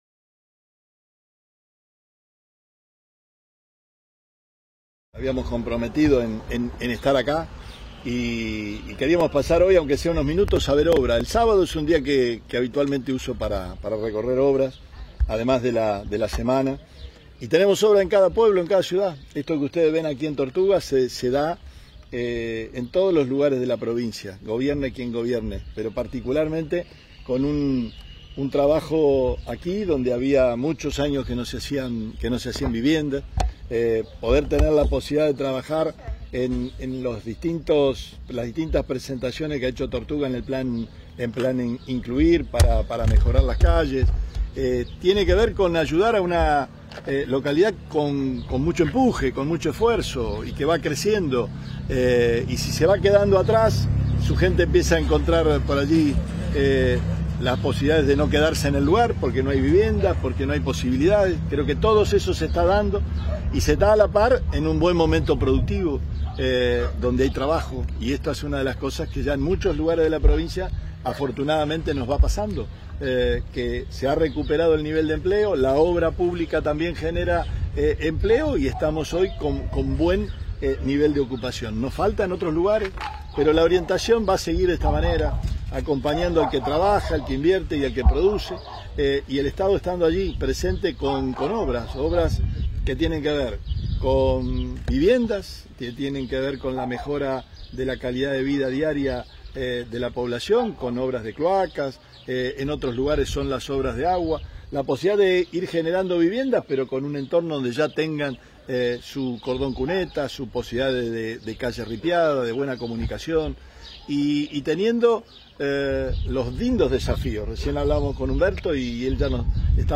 En Tortugas